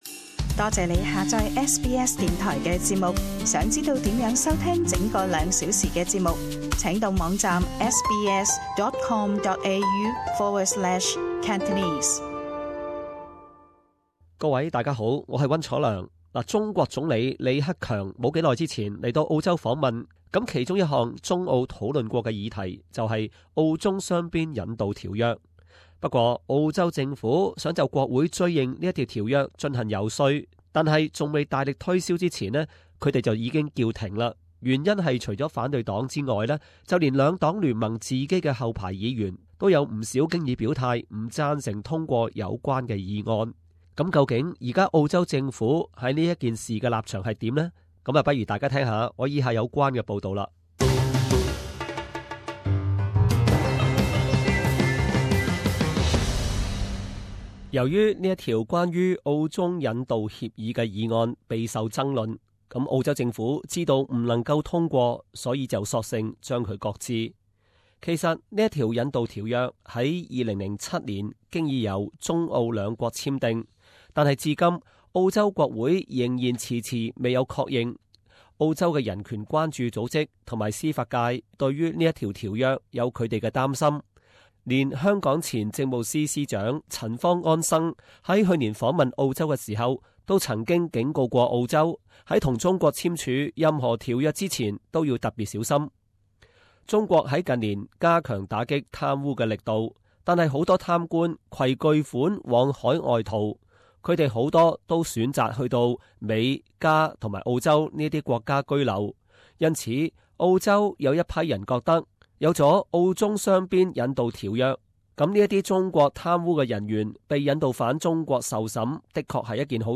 【時事報導】 澳洲政府暫擱置追認澳中雙邊引渡條約